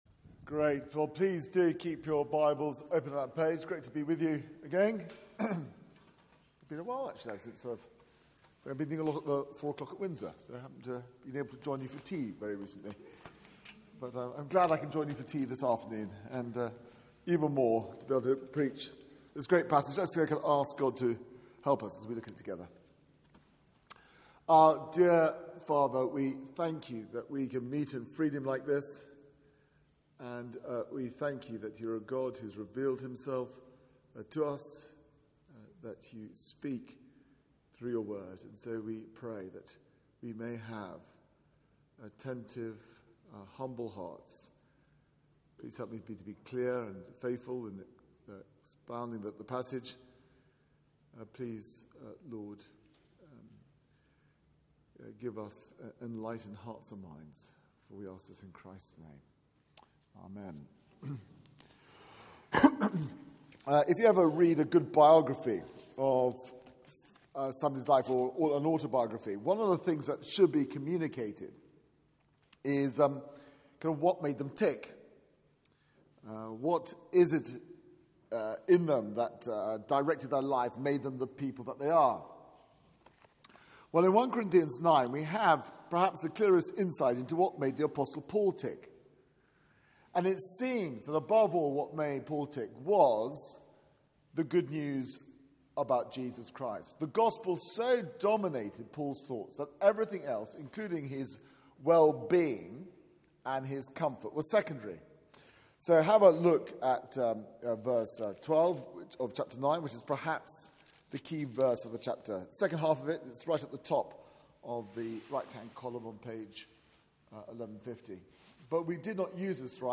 Media for 4pm Service on Sun 31st May 2015 16:00 Speaker